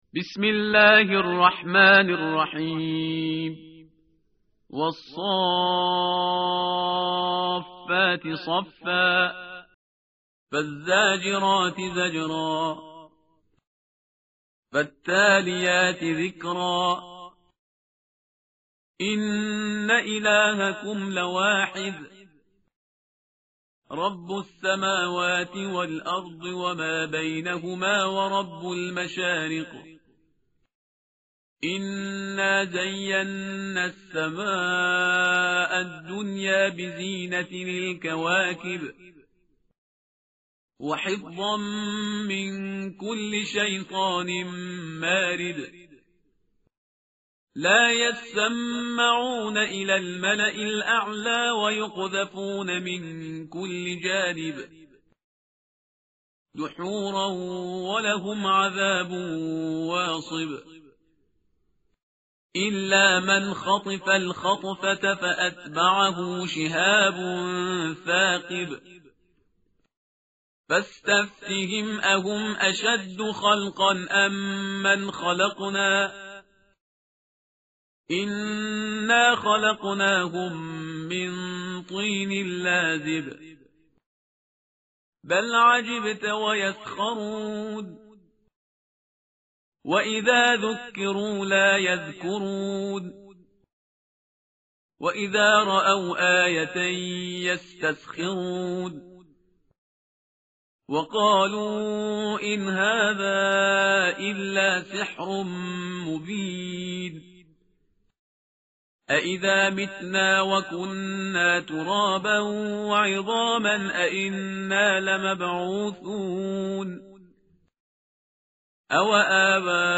tartil_parhizgar_page_446.mp3